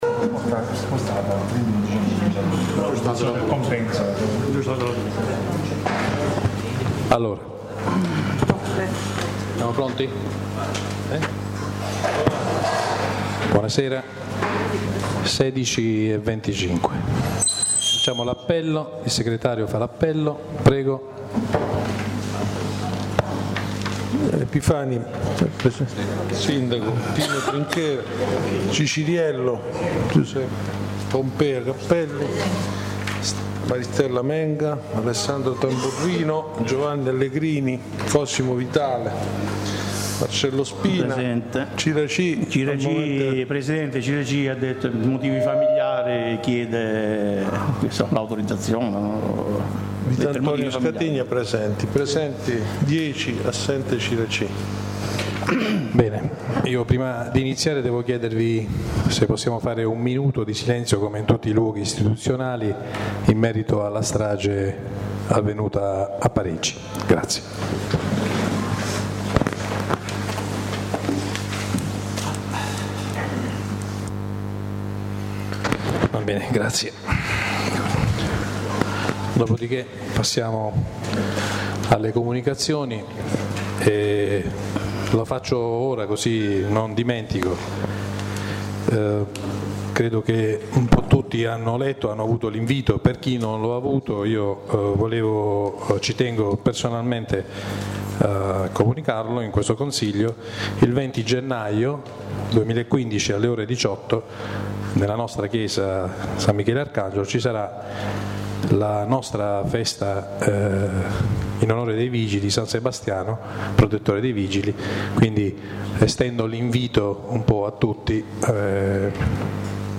La registrazione audio del Consiglio Comunale di San Michele Salentino del 15/01/2015